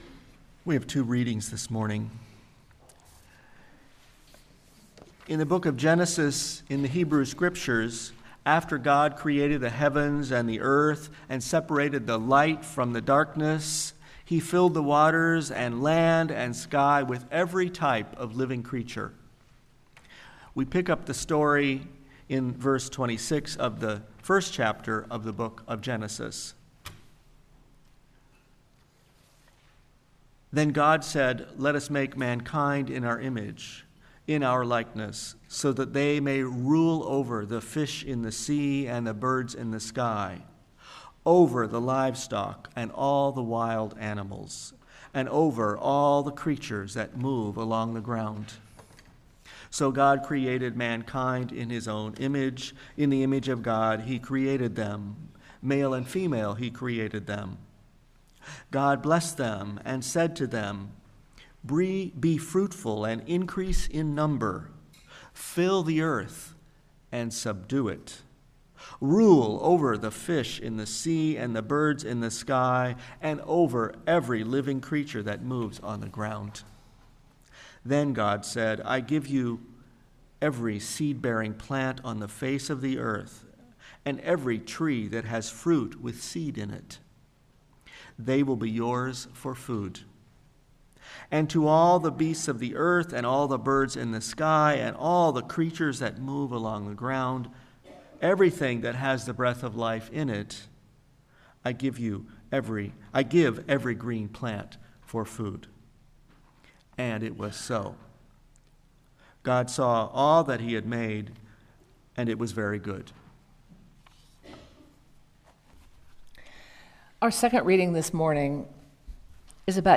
Sermon-Earth-Air-Fire-Water.mp3